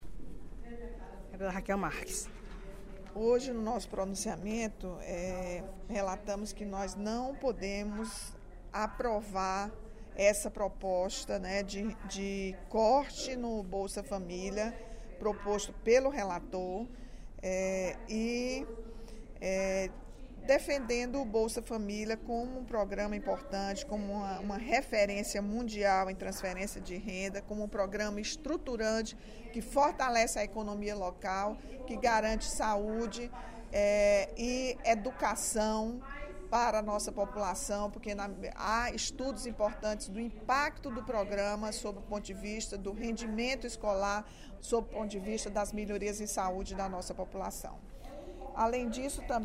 A deputada Rachel Marques (PT) externou, nesta sexta-feira (23/10), durante o primeiro expediente da sessão plenária, repúdio à proposta do relator do projeto de lei orçamentária da União para 2016, deputado federal Ricardo Barros (PP-PR), de cortar parte dos recursos previstos para o próximo ano para o programa Bolsa Família.
Em aparte, a deputada Fernanda Pessoa (PR) reforçou a importância do programa para a população brasileira, em especial para o Ceará.